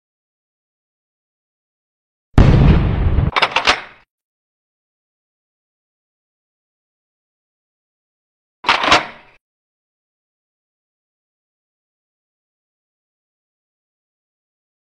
Benelli M4 Super 90 Shotgun Sound Effects Free Download